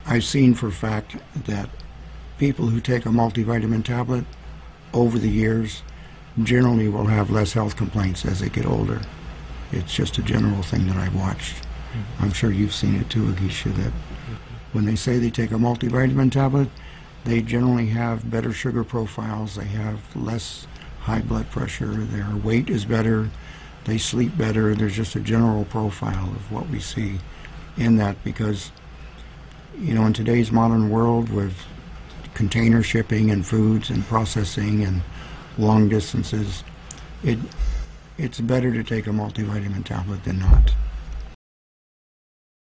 The latest Let’s Talk Show discussion featured guests from the Jade Medical Centre in Newcastle, Nevis sharing their knowledge on health and natural medicine.